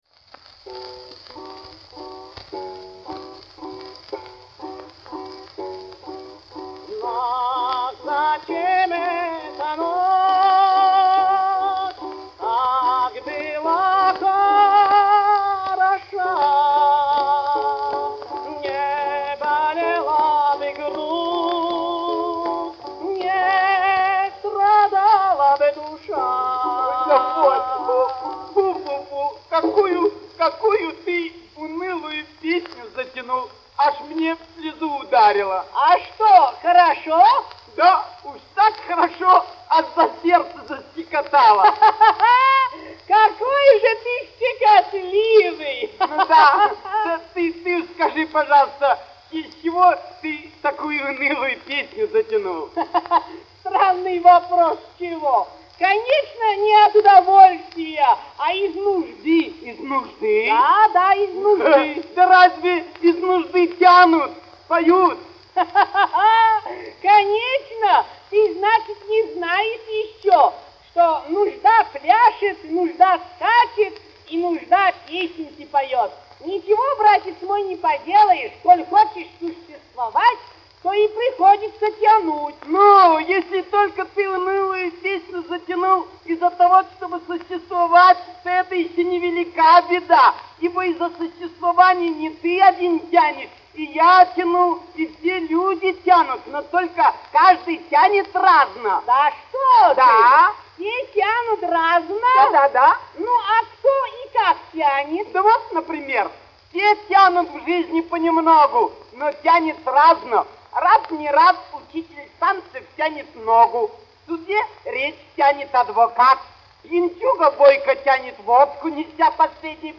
музыкальные клоуны.
Каталожная категория: комическая сцена с гитарой |
Жанр: Комическая сцена
Вид аккомпанемента: гитара
Место записи: С.-Петербург |